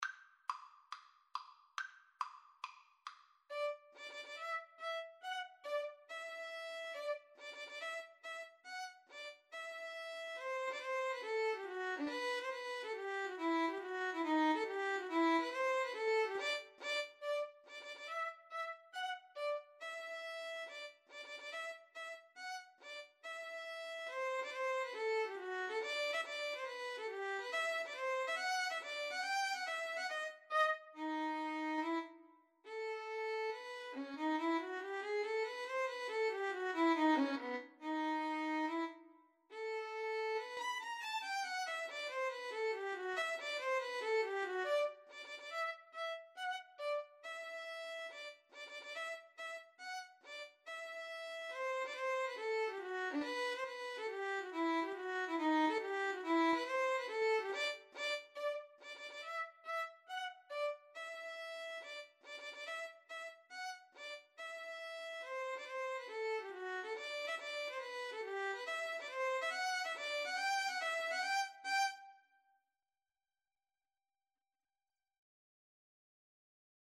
Tempo di marcia =140
Classical (View more Classical Violin-Viola Duet Music)